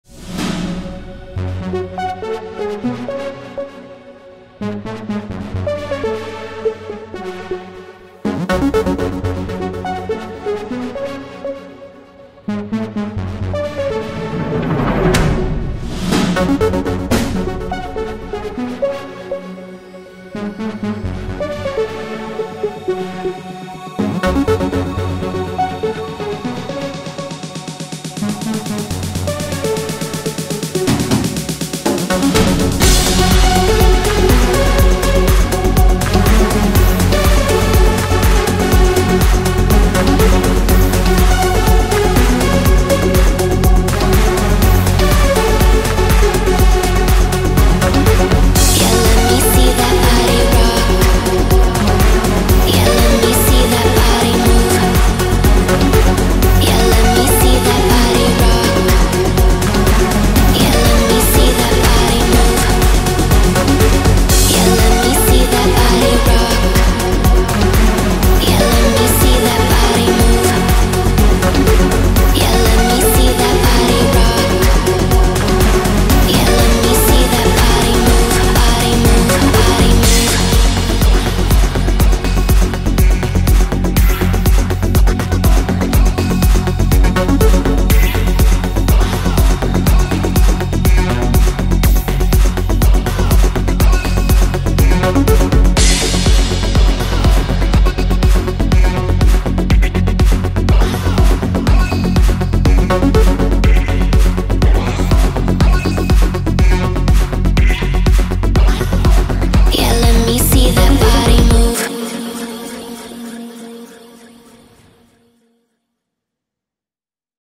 These sounds bring beauty, melody, and emotional depth into your music while adding a rich analog character that instantly enhances any production.
• Progressive Trance
• Melodic Techno
Whether you build atmospheric leads, powerful basslines, evolving sequences, or lush pads, these presets enrich your sound and give your tracks a polished, professional character.
WALDORF-PULSE-2-SOUNDBANK.mp3